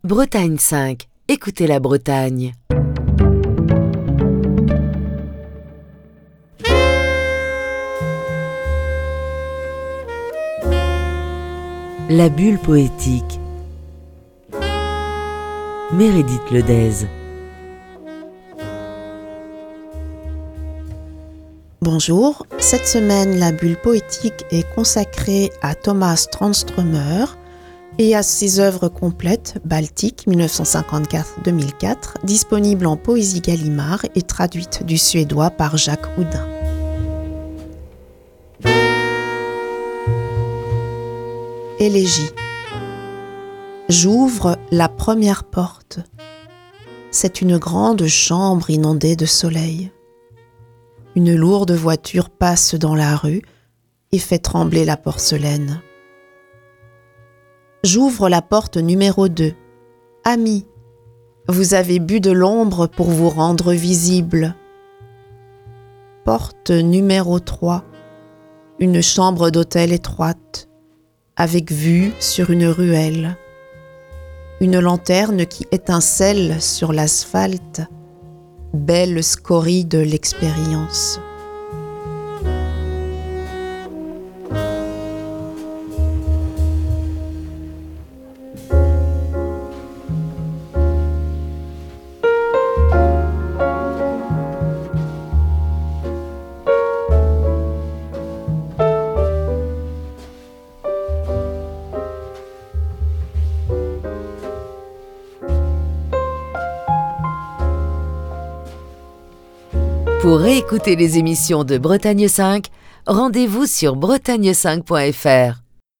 lit des textes du poète suédois Tomas Tranströmer